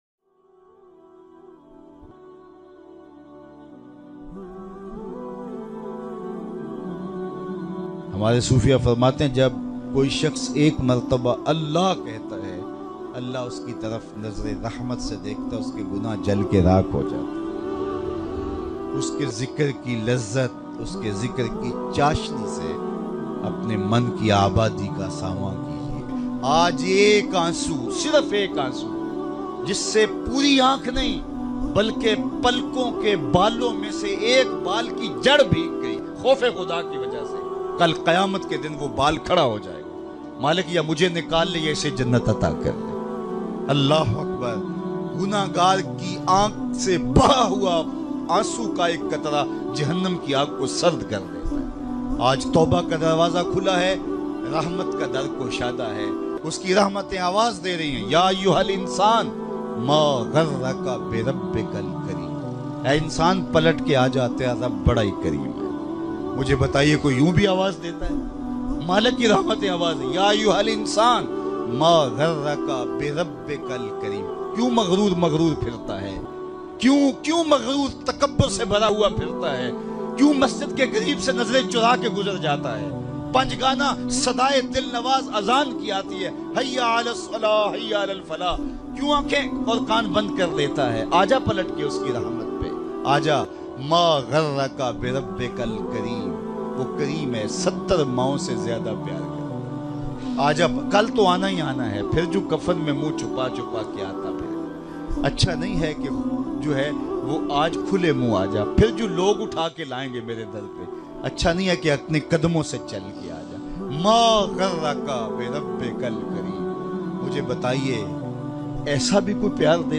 Jab Koi ALLAH Kehta Hai Rula Dene Wala Bayan MP3 Download
Jab Koi ALLAH Kehta Hai Rula Dene Wala Bayan.mp3